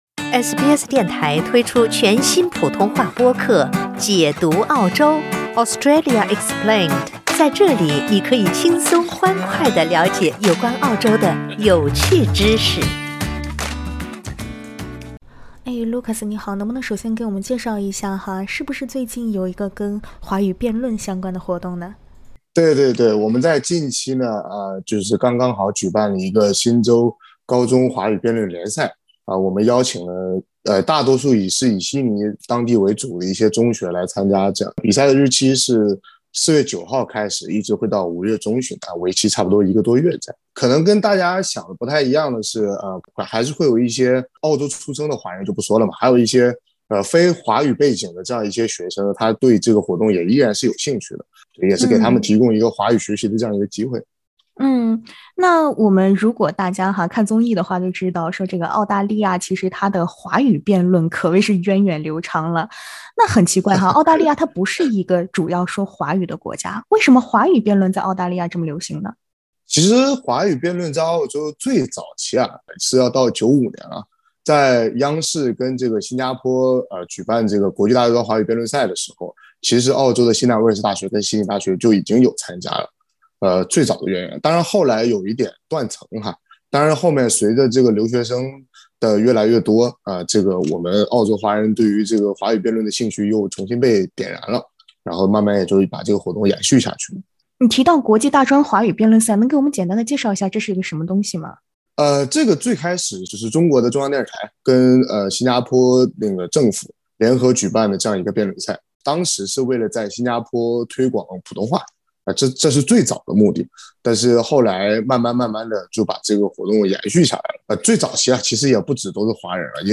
请听采访： LISTEN TO 在澳大利亚这样的英语国家，为什么华语辩论盛行？